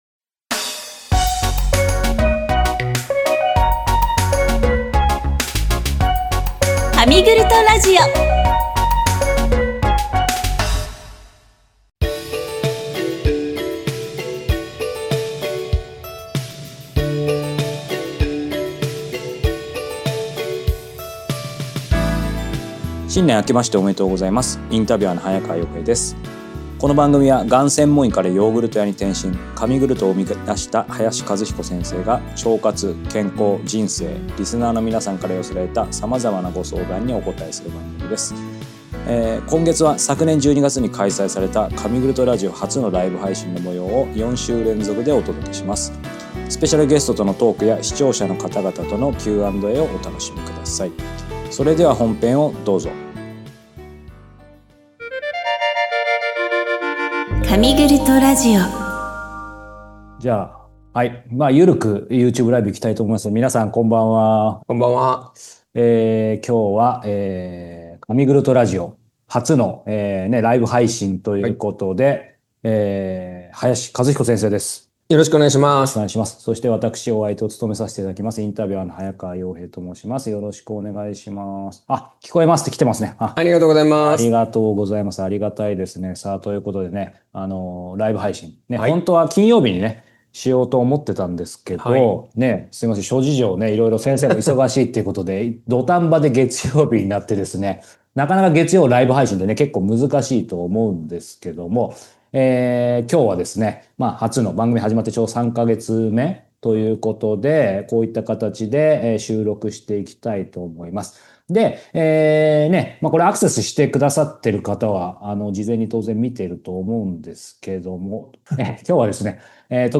2025年12月8日に開催した神グルトラジオ初のライブ配信を全4回シリーズでお届けします。